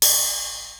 ridehatopen.wav